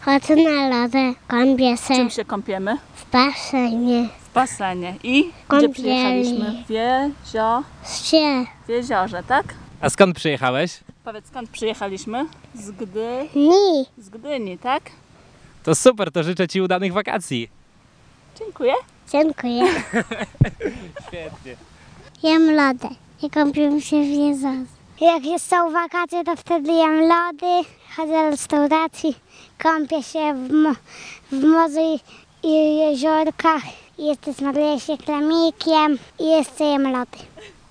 sonda-upały-2.mp3